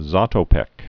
(zätō-pĕk), Emil 1922-2000.